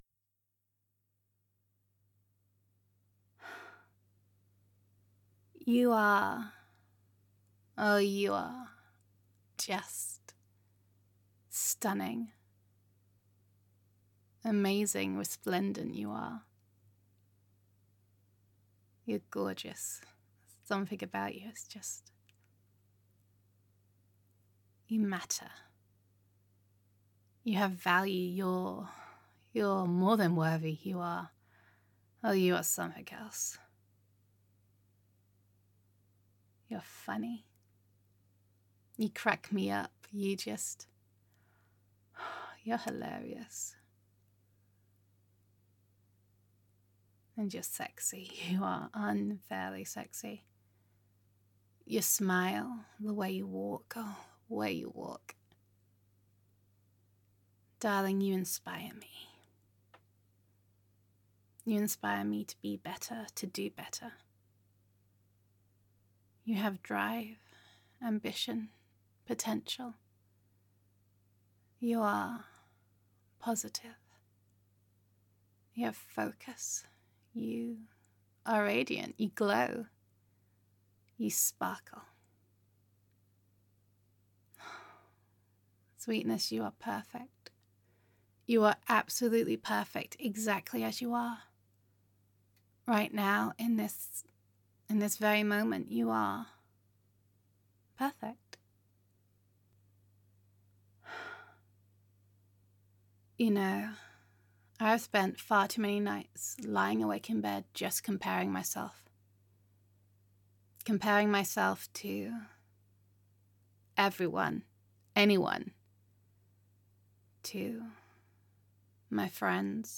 [F4A] In This Moment You Are Perfect [Stop Comparing Yourself][Focus on the Positive][Self Love][Reassurance][Gender Neutral][Girlfriend Roleplay]